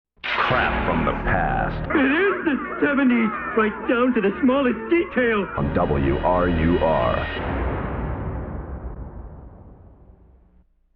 Station and program identification